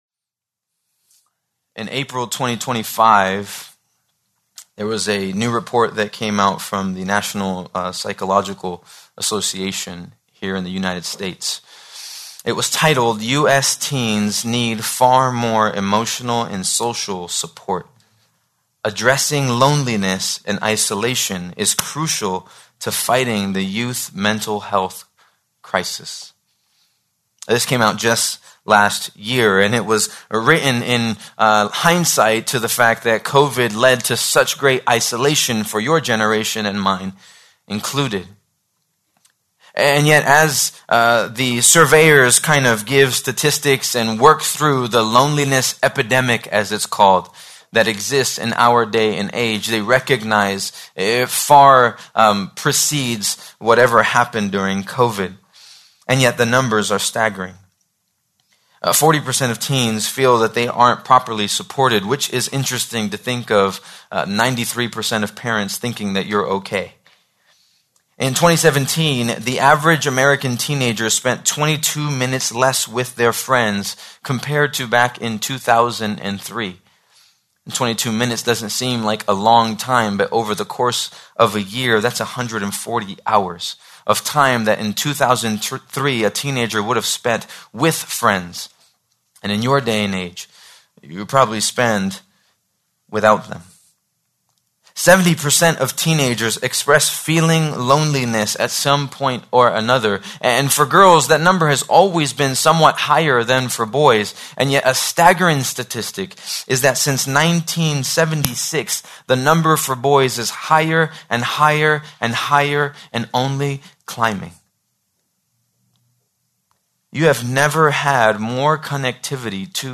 Please note that, due to technical difficulties, this recording skips brief portions of audio.